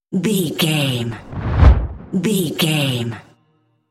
Dramatic whoosh deep trailer
Sound Effects
Atonal
dark
intense
whoosh